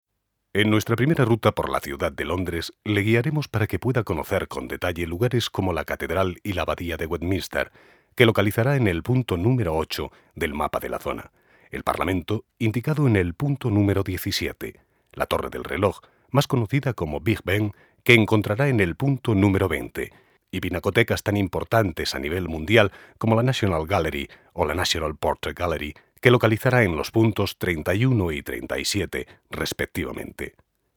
Voz grave y elegante.
kastilisch
Sprechprobe: eLearning (Muttersprache):
Deep voice and elegant.